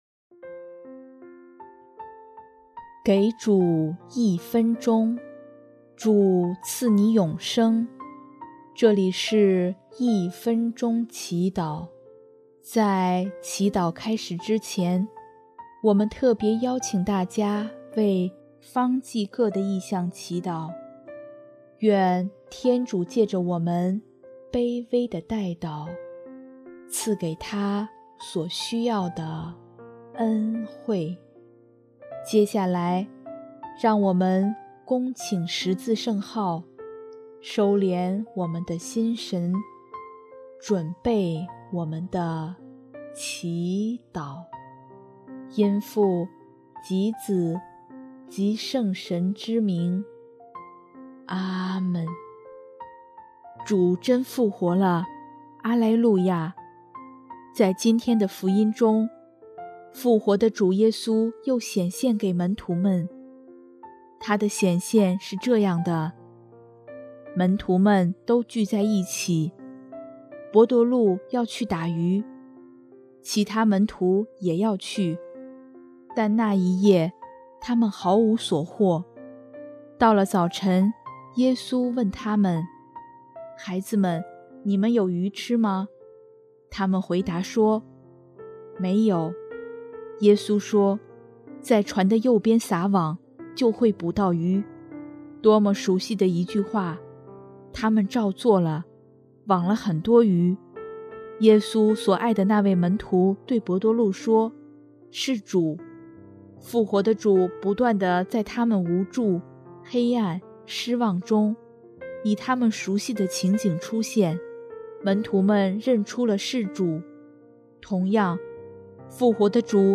音乐： 第四届华语圣歌大赛参赛歌曲《红色恩典》（方济各：为刚去世的教宗祈祷，安息主怀）